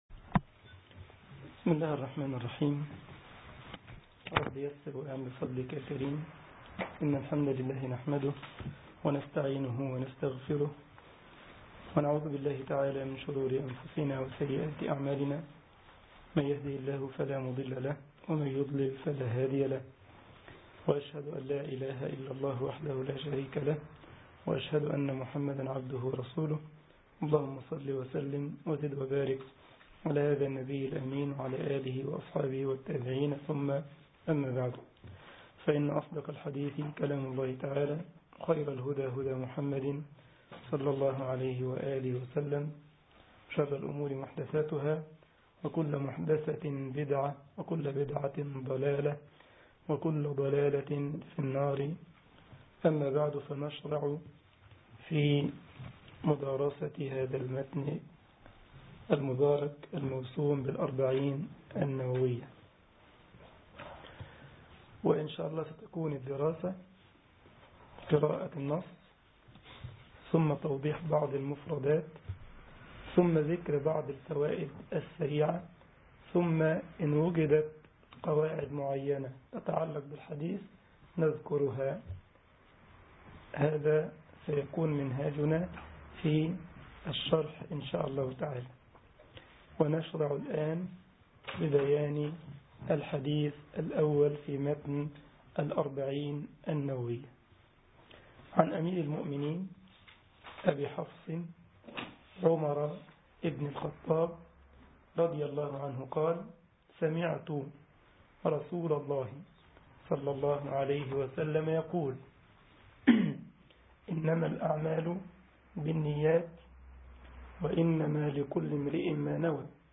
محاضرة
جمعية الشباب المسلمين بسلزبخ ـ ألمانيا